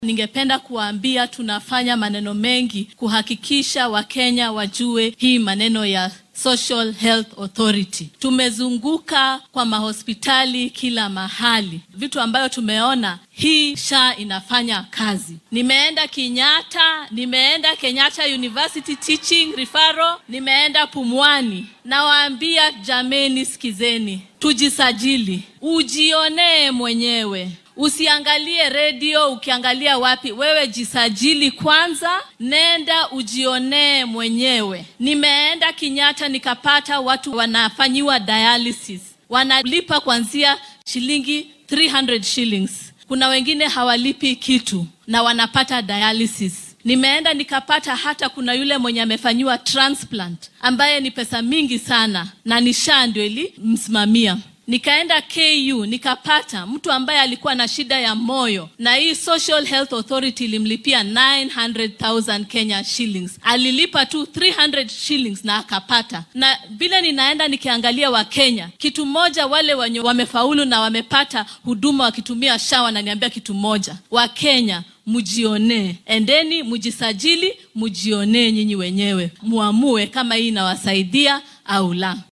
Wasiiradda wasaaradda caafimaadka ee dalka Dr.Deborah Barasa ayaa sheegtay in uu shaqeynaya caymiska caafimaadka bulshada ee SHA ee lagu beddelay nidaamkii hore ee NHIF. Xilli ay maanta munaasabad kaniiseed uga qayb gashay ismaamulka Kakamega ayay Barasa hoosta ka xarriiqday in ay booqatay isbitaallo dhowr ah oo uu ka mid yahay isbitaalka qaran ee Kenyatta . Waxay intaasi ku dartay in ay xaqiijisay in caymiskan uu bukaanno badan u fududeeyay in ay helaan daryeel caafimaad sida sifeynta kilyaha.